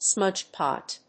アクセントsmúdge pòt